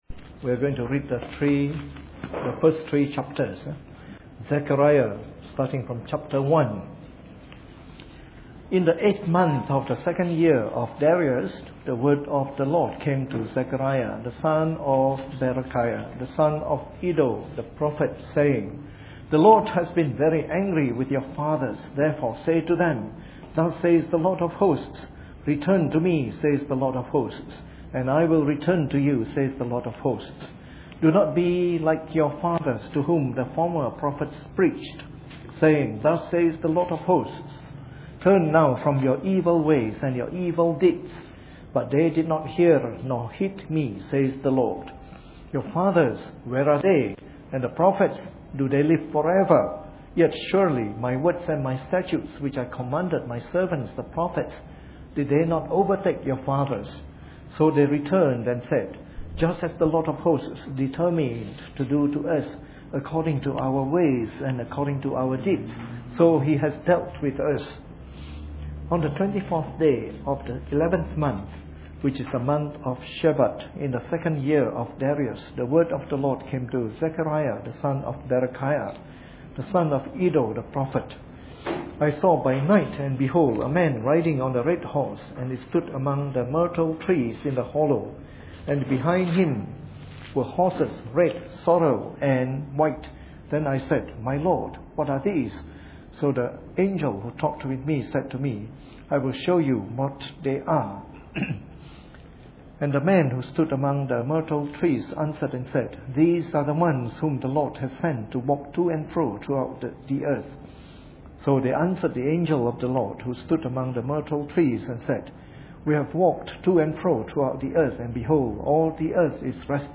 Preached on the 6th of February 2013 during the Bible Study, from our series on “The Minor Prophets.”